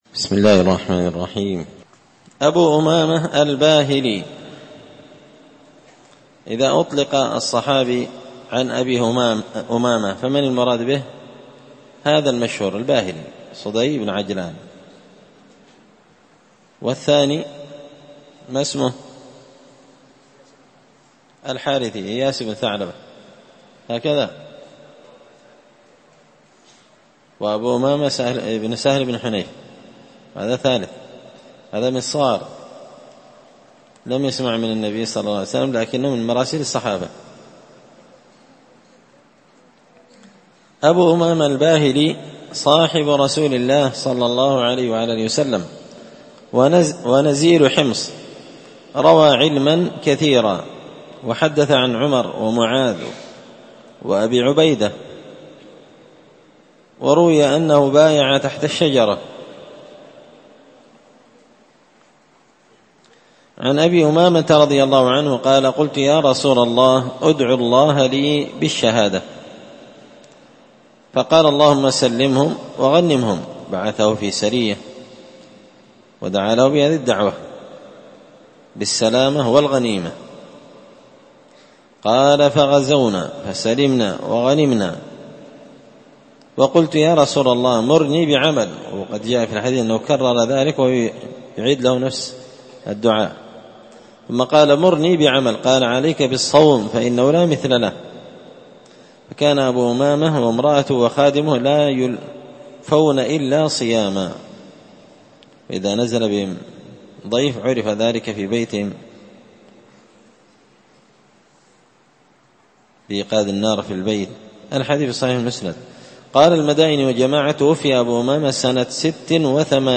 الدرس 204 أبو أمامة الباهلي قراءة تراجم من تهذيب سير أعلام النبلاء